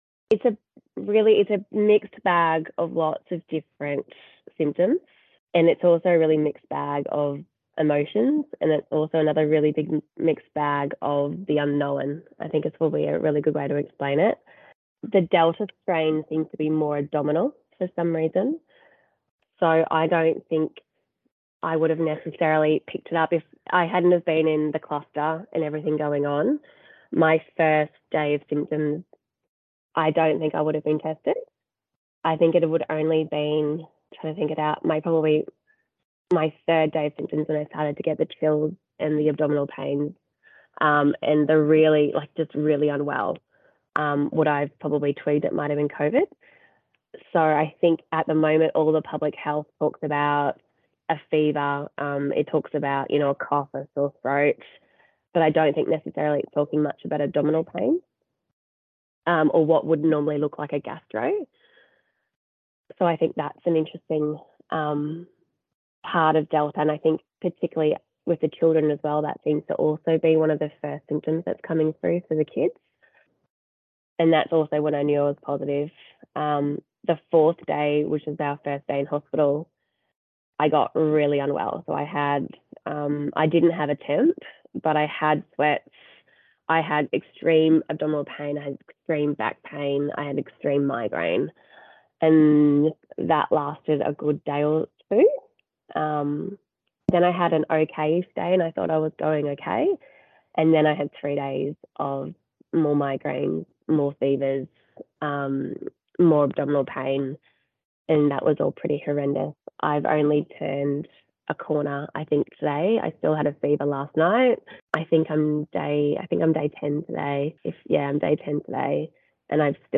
A woman who is recovering from Covid in the Sunshine Coast University Hospital is imploring parents to get vaccinated, saying the virus is a "beast" and she has never been so sick.